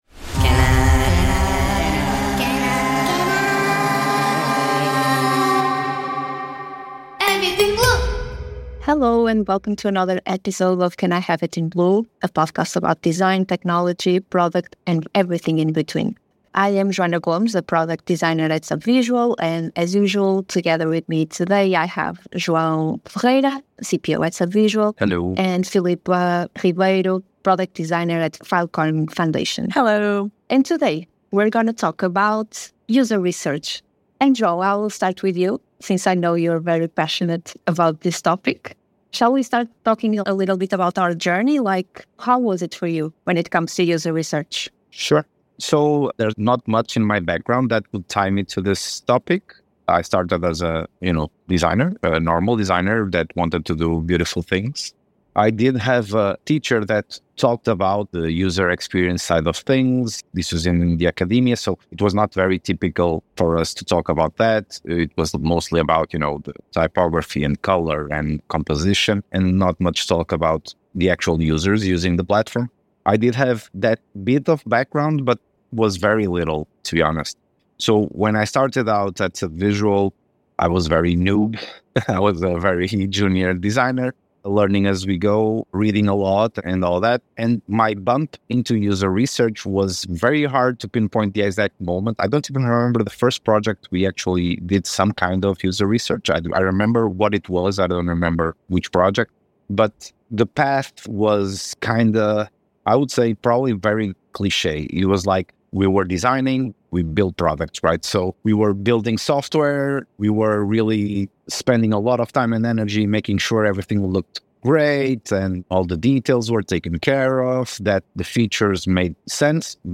The conversation begins with personal reflections on how user research became integral to their design processes, overcoming initial challenges and evolving their techniques. They underscore the importance of initiating user testing early, even with basic prototypes, to gather valuable insights.